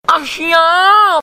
Efek suara Ashiap
Kategori: Suara viral
Keterangan: Sound effect/ efek suara Ashiap! sering digunakan dalam meme dan edit video untuk menambah semangat atau keseruan.
efek-suara-ashiap-id-www_tiengdong_com.mp3